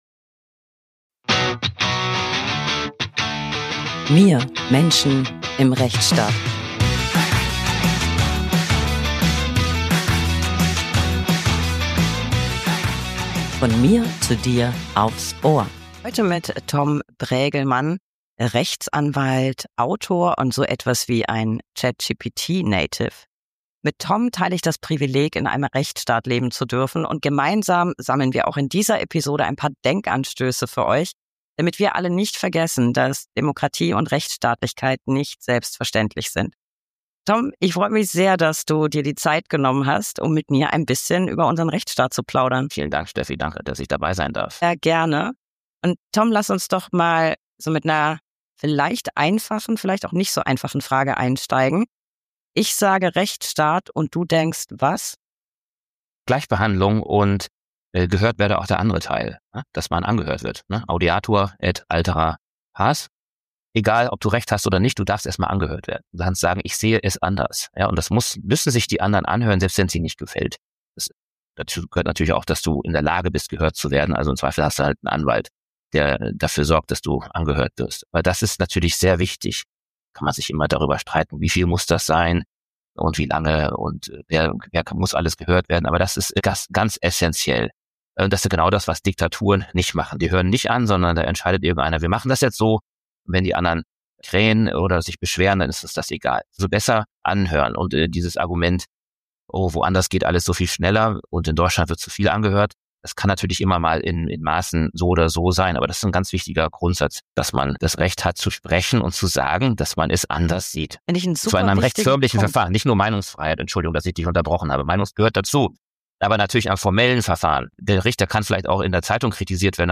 … continue reading 184 episoade # Gesellschaft # Politik # Bildung # Nachrichten # Bundesrechtsanwaltskammer # Anwalt # Rechtsanwalt # Recht # Talk # Interview # Gerichte # Rechtsstaat # Brak # Anwaltschaft